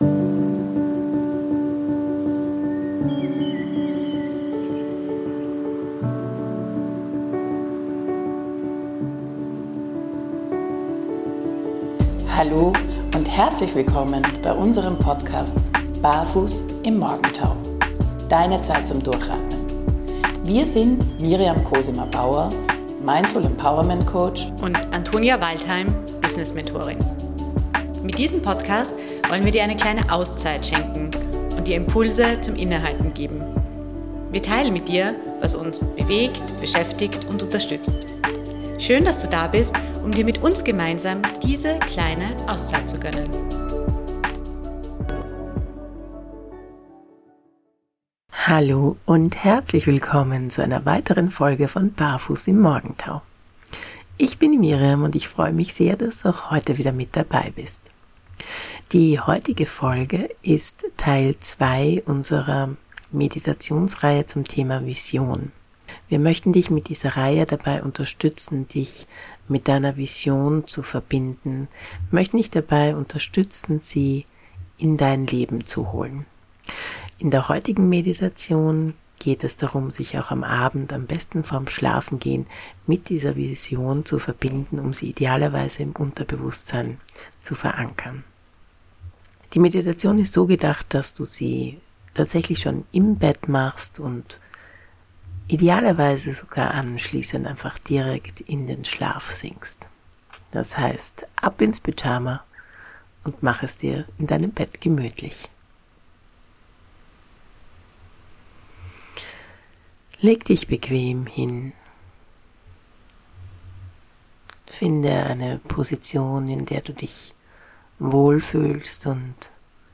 In dieser Folge erwartet dich ergänzend zu der Morgenmeditation aus Episode #30 eine ganz besondere geführte Visions-Meditation, die dich sanft in den Schlaf begleitet und dabei unterstützt, deine Vision zu festigen.